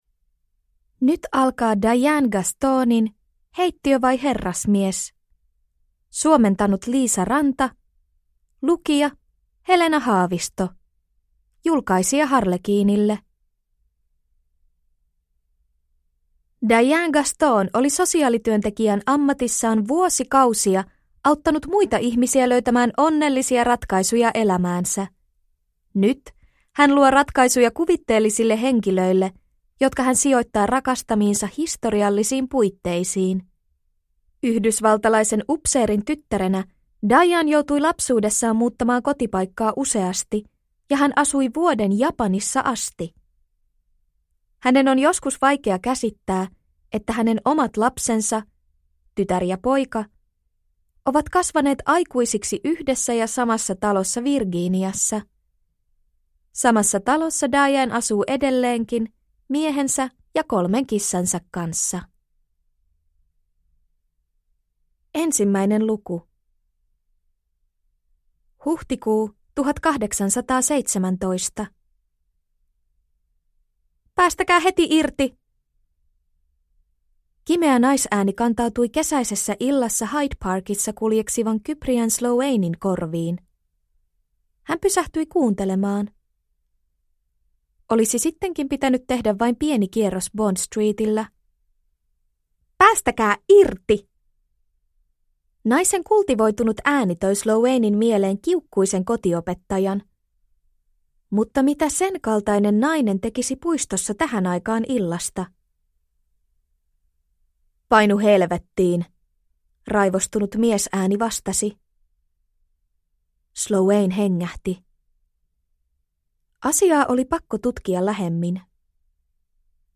Heittiö vai herrasmies? (ljudbok) av Diane Gaston